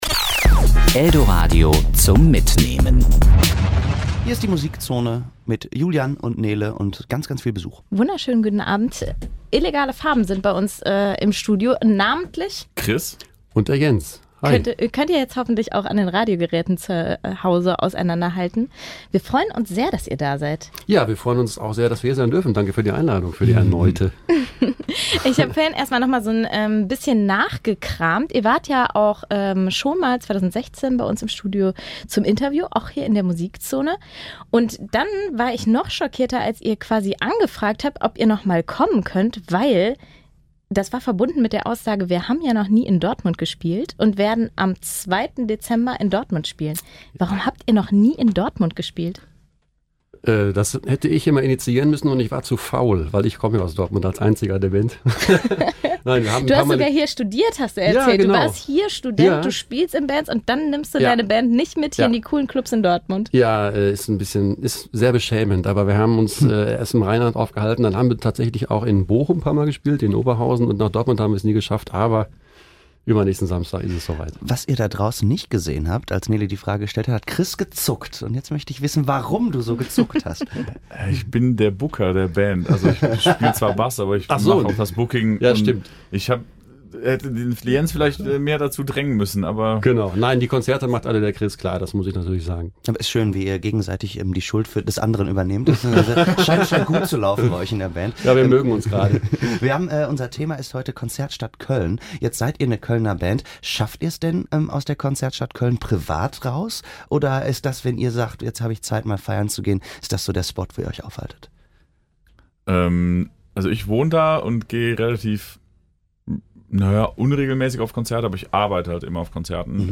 Illegale Farben im Musikzone-Interview
Serie: Interview Sendung: Musikzone